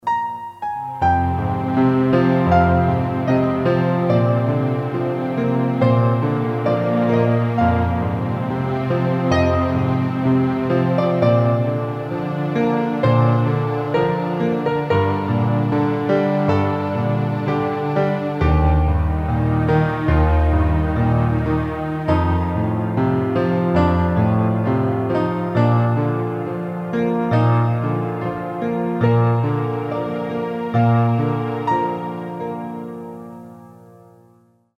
06. Postludio in Mi bemolle.mp3